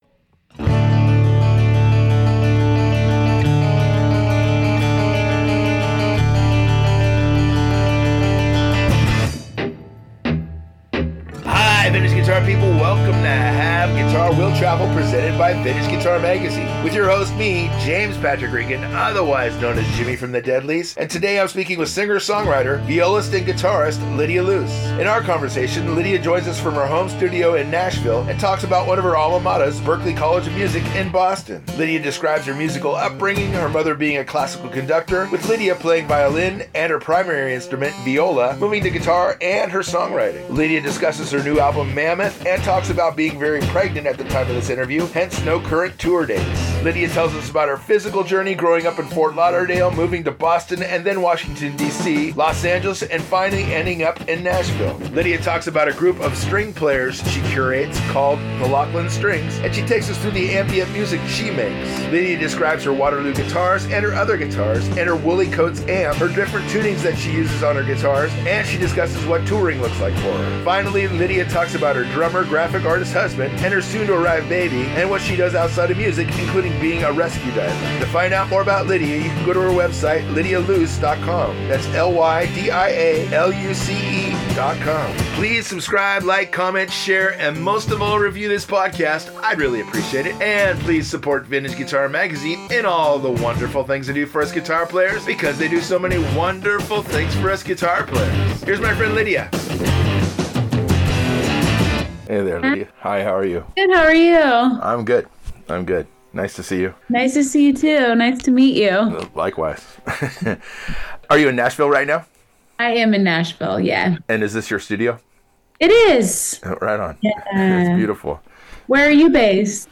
speaks with singer/songwriter violist and guitarist
joins us from her home studio in Nashville